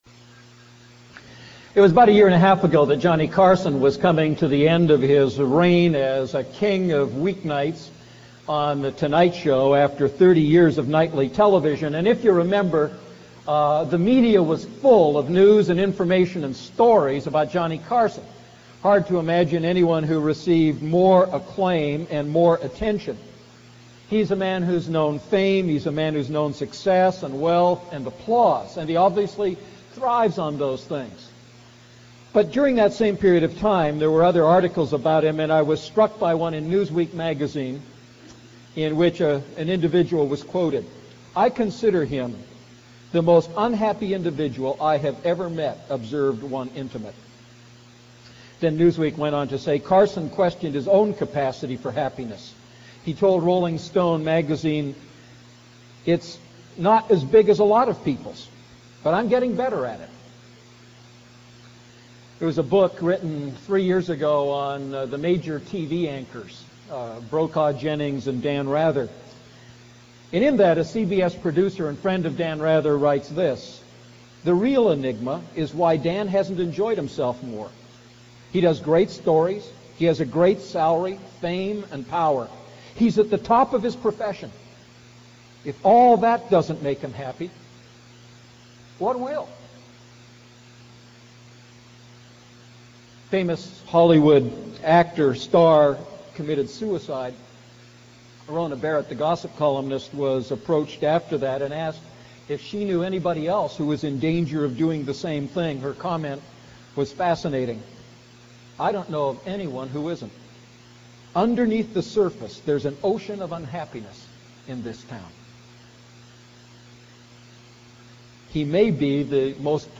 A message from the series "Luke Series I."